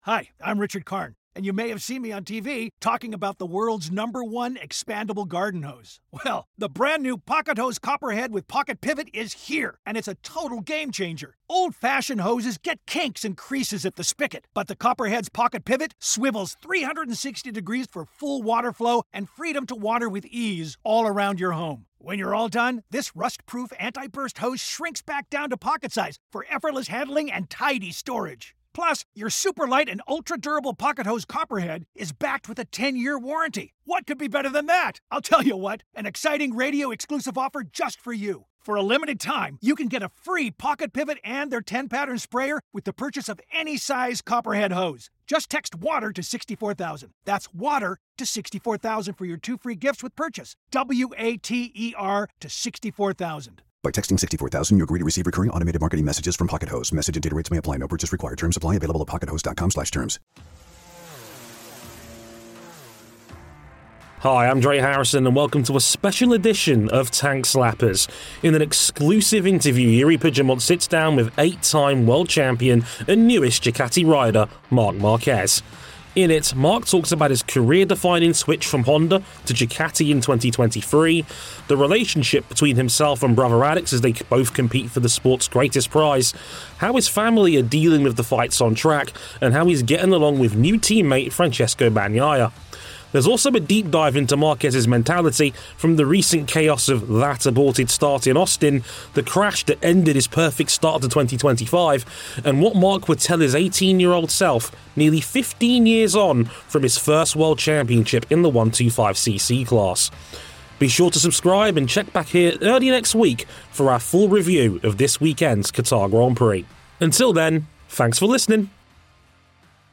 In an exclusive interview